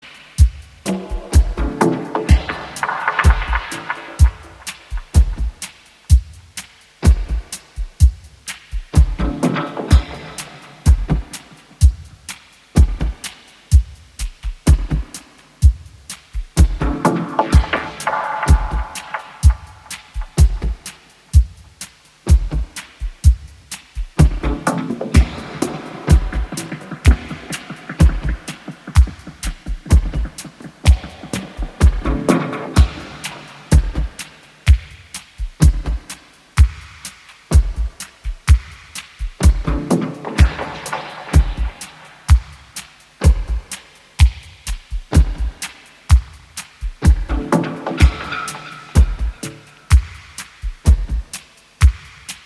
アルバム前2作が気に入った方なら今回も間違いない、純度高く洗練を極めたアーシー・モダン・テック・ダブ。